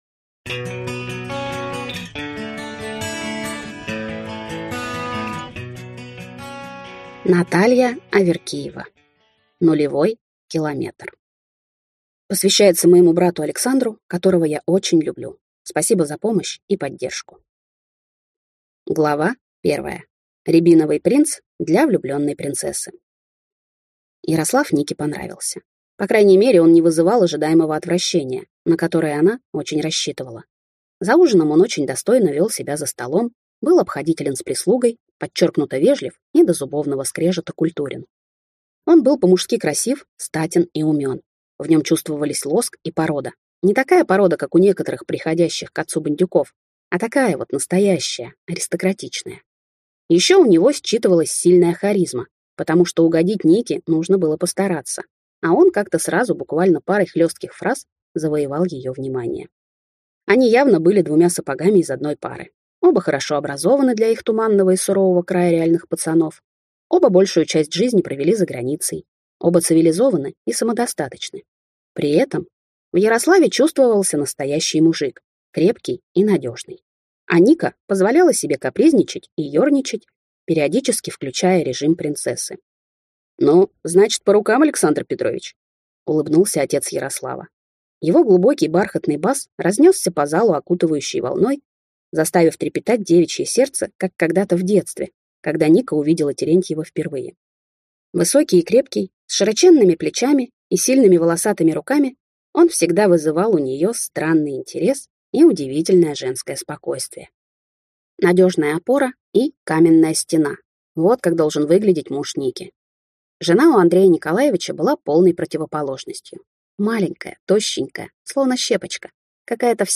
Аудиокнига Нулевой километр | Библиотека аудиокниг